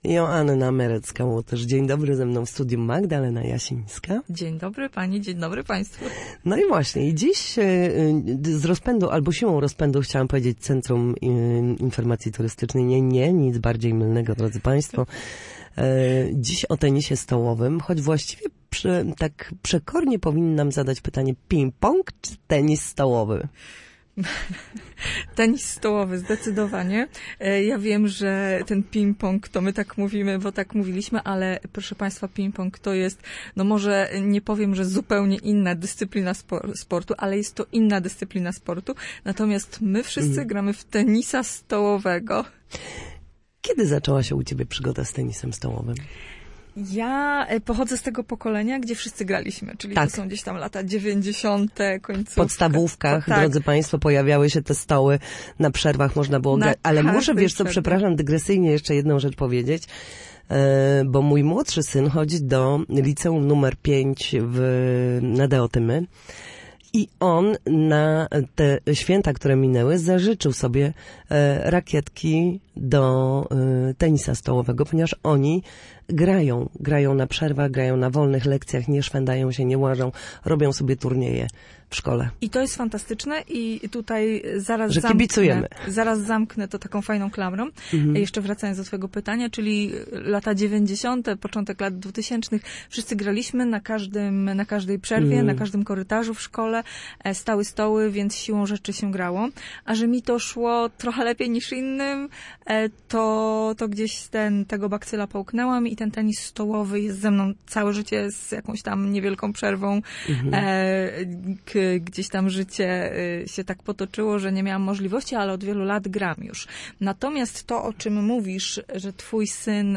mówiła w Studiu Słupsk Radia Gdańsk